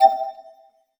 ui_menu_button_beep_11.wav